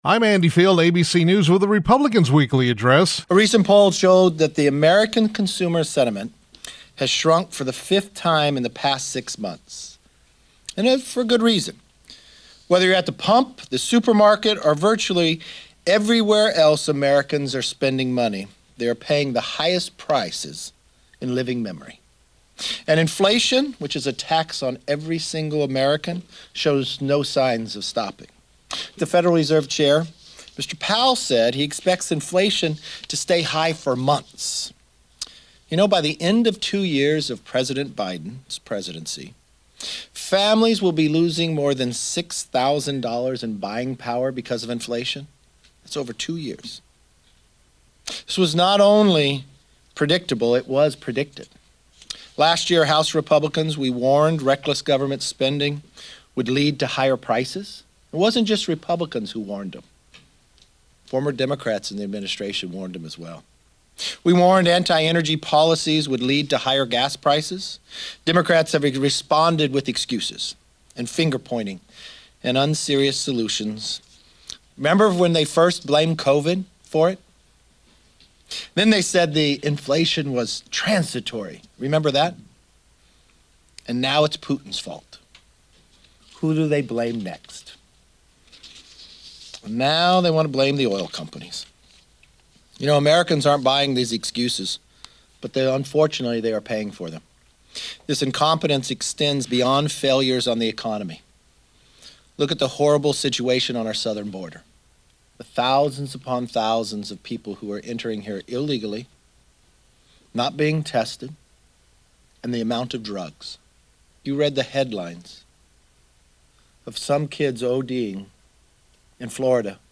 McCarthy was Tuesday’s KVML “Newsmaker of the Day”. Here are his words: